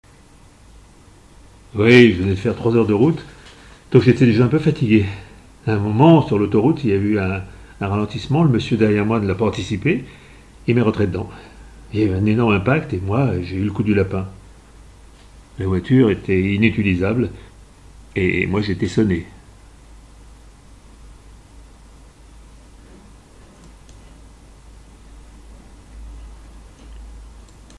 - Ténor
Voix off